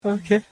Ok sound effect
ok_-sound-effect-made-with-Voicemod-technology.mp3